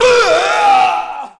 zombie_infection_2.mp3